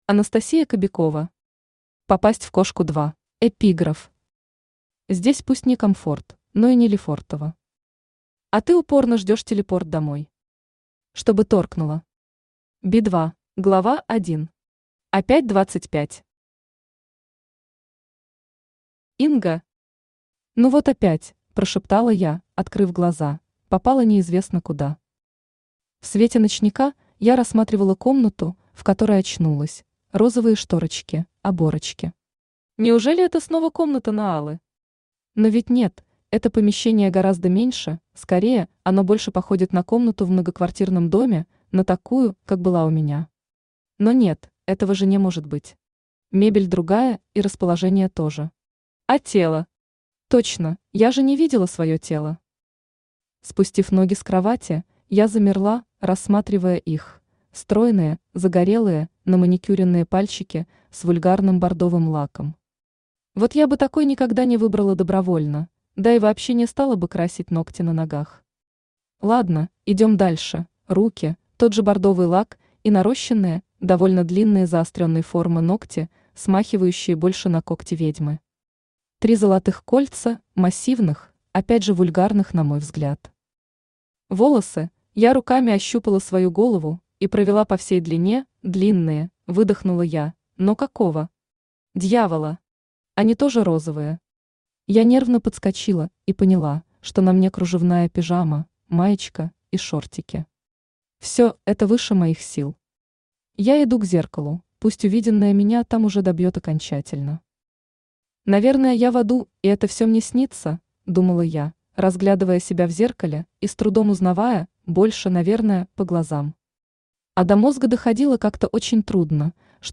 Аудиокнига Попасть в кошку 2 | Библиотека аудиокниг
Aудиокнига Попасть в кошку 2 Автор Анастасия Кобякова Читает аудиокнигу Авточтец ЛитРес.